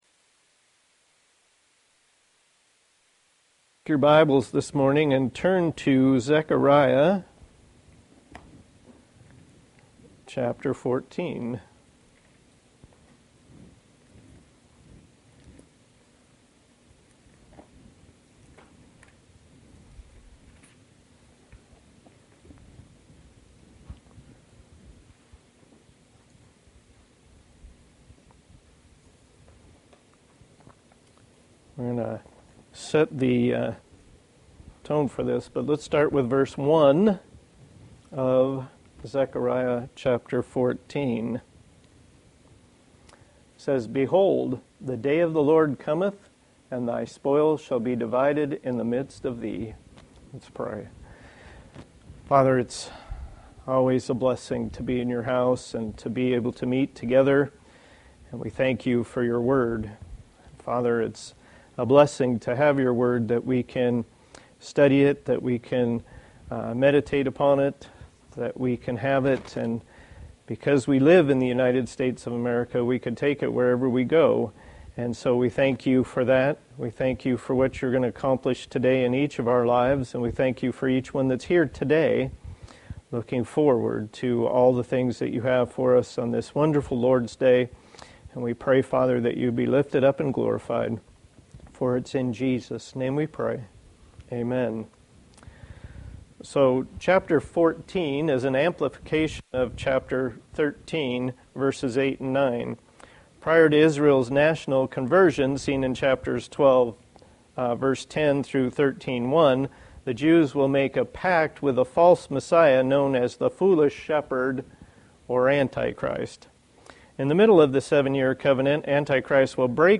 Passage: Zechariah 14:1 Service Type: Sunday School